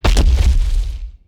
Boss2ZombieAttack2.mp3